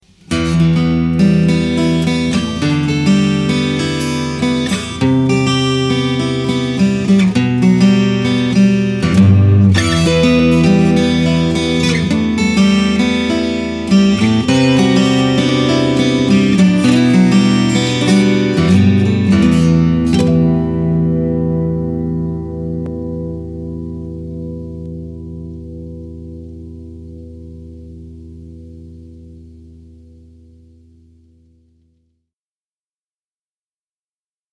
12 string Jumbo Euro Spruce/Red Gum, now with sound
But this one all is just right.
It looks satin so you expect a walnut like sound, but it has an excellent good clear ring to it.
BTW: the sustain of the sound seems manupulated. But honestly, this thing rings forever.